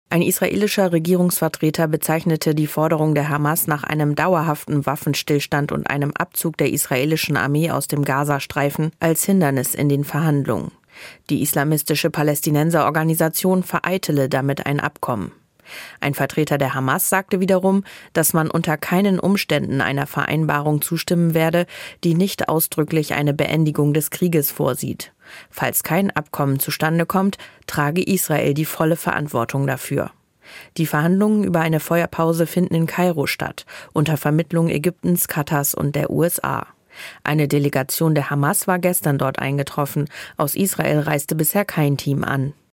In den indirekten Verhandlungen über eine Feuerpause im Gaza-Krieg zwischen Israel und der Hamas gibt es offenbar keine Fortschritte. Beide Seiten weisen sich gegenseitig die Schuld dafür zu. Aus der Nachrichtenredaktion